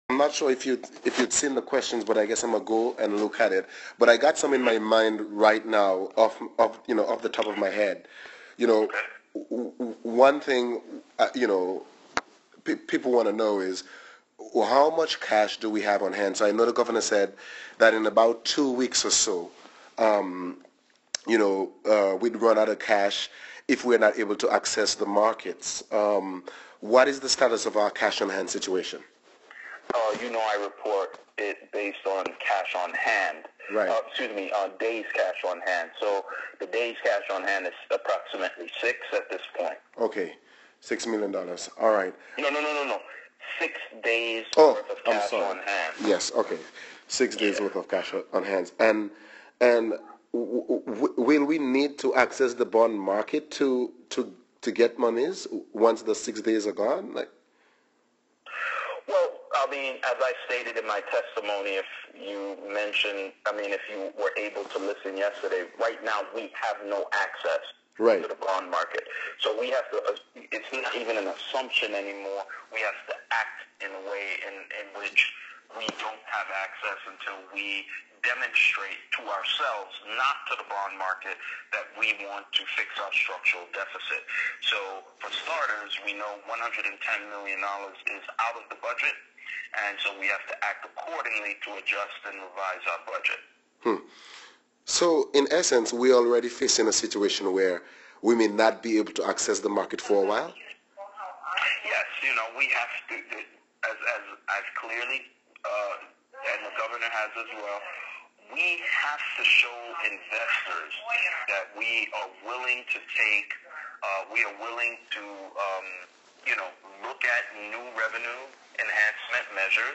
ST. CROIX — During a press conference held on January 13 at Government House here, Governor Kenneth Mapp spoke in blunt terms about the state of the territory, and the urgency to pass his sin tax measure if the territory were to avert a financial crisis.